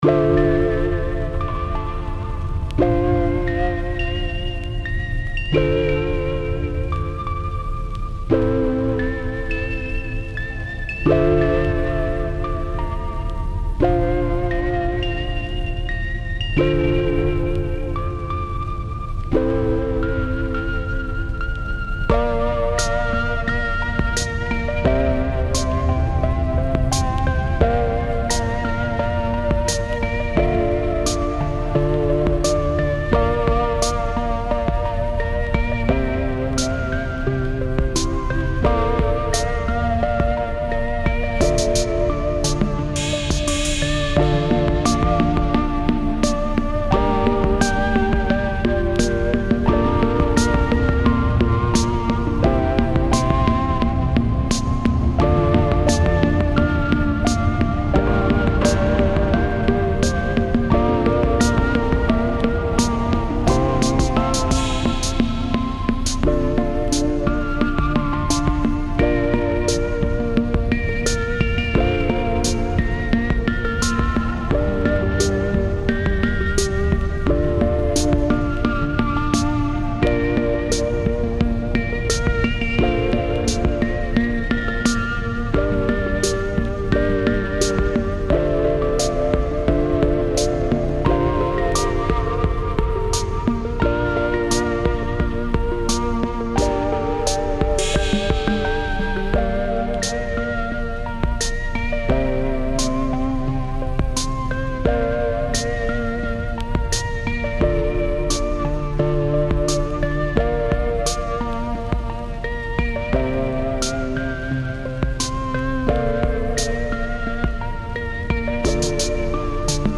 What you'll hear in the podcast are our latest sounds - either a field recording from somewhere in the world, or a remixed new composition based solely on those sounds.